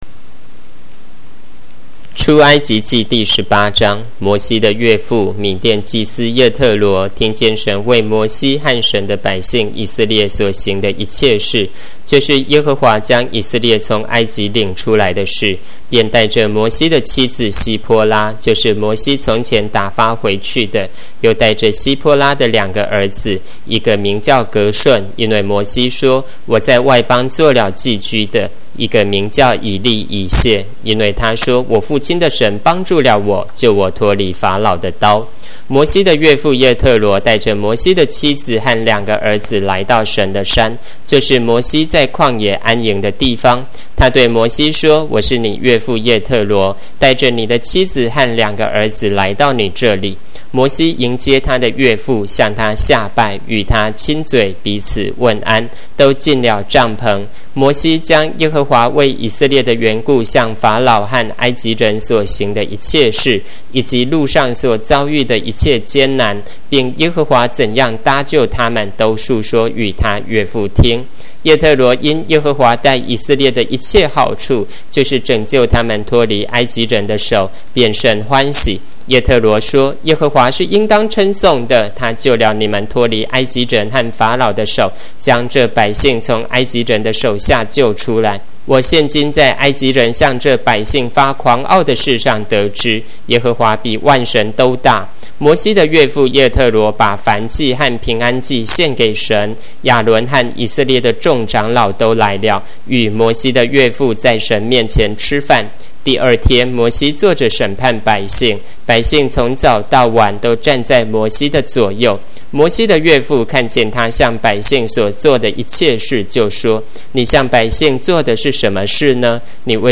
Spring 版和合本有聲聖經